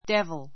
devil dévl デ ヴる 名詞 ❶ 悪魔 あくま Speak [Talk] of the devil and he will appear.